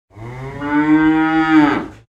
cow_moo3.ogg